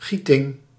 Ääntäminen
IPA : /ˈkæs.tɪŋ/